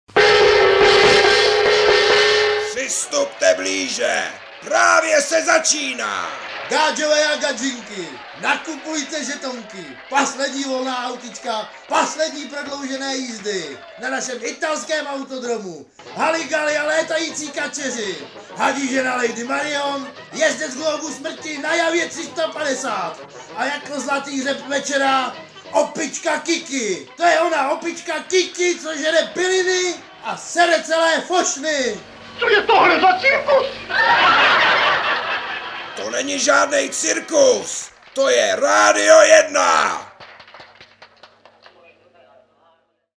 (27/2) Máme tady takový pěkný jingle